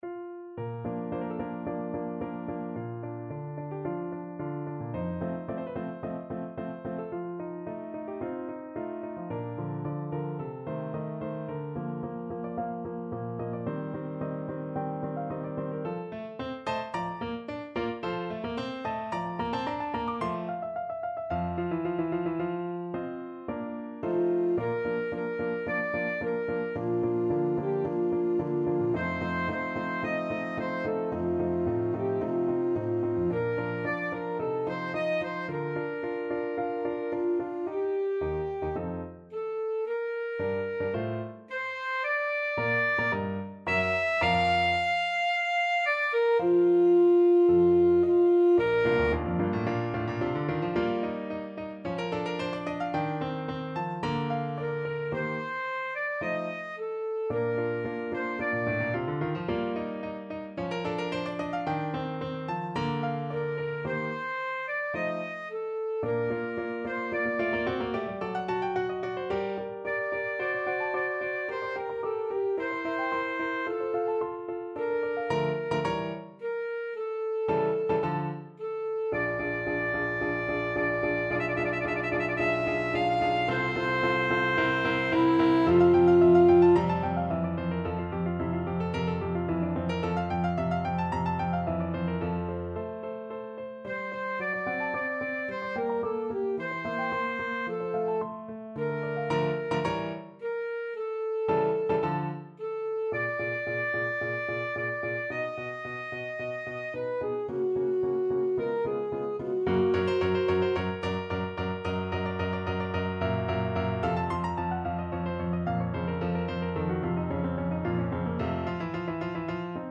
Alto Saxophone
4/4 (View more 4/4 Music)
Andante =110
Classical (View more Classical Saxophone Music)